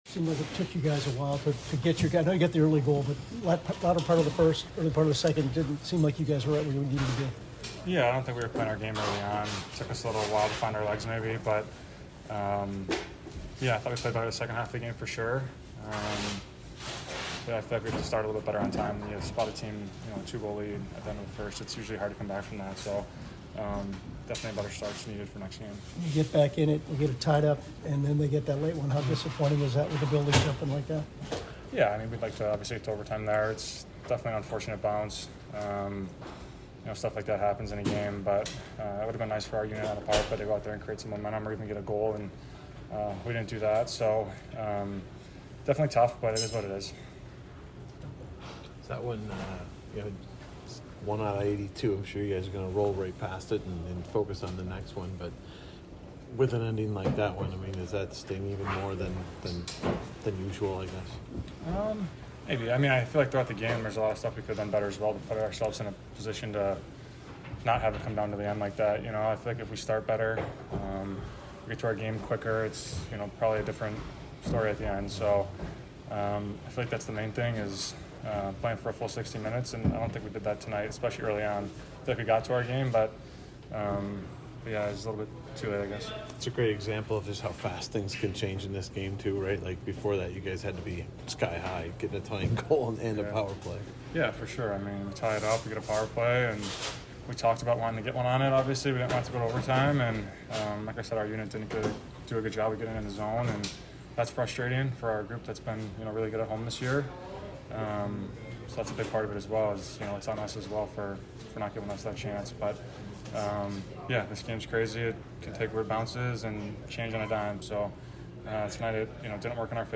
BLUE JACKETS POST-GAME AUDIO INTERVIEWS